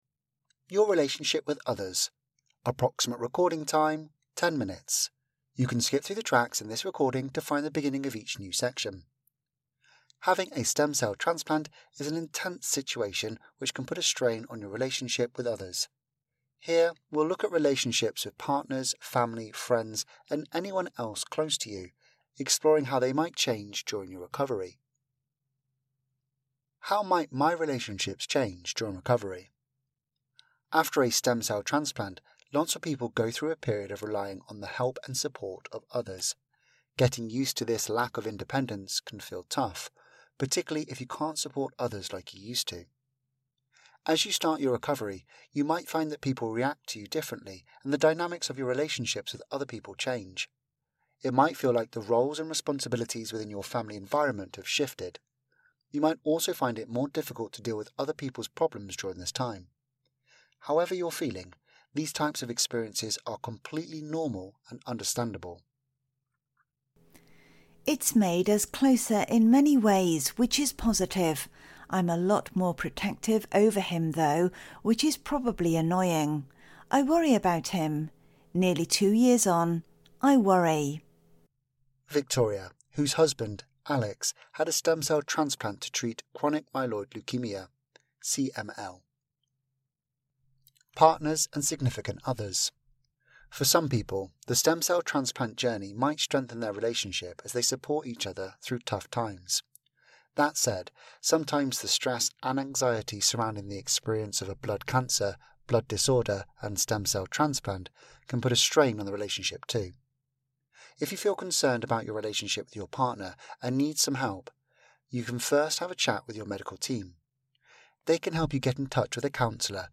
Audio version of Anthony Nolan's patient information: Your relationships with others